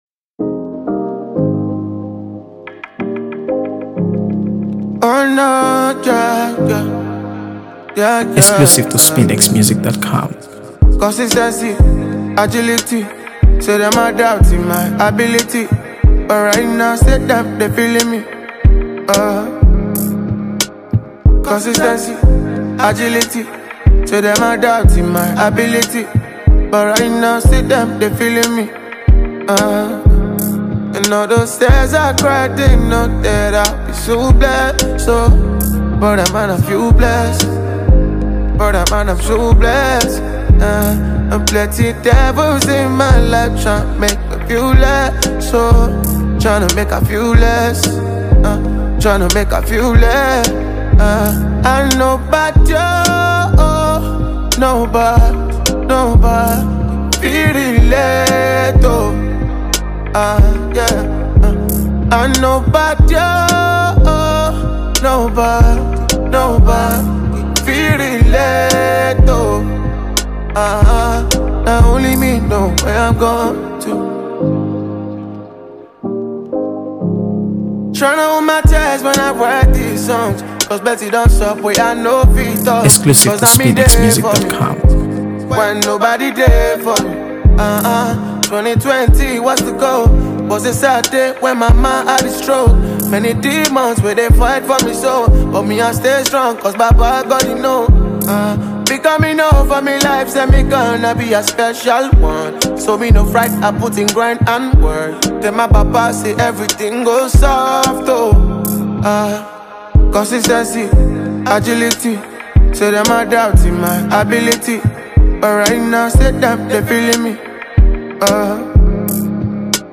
AfroBeats | AfroBeats songs
emotive vocals to shine.
It’s a soulful, feel-good track with a message that sticks.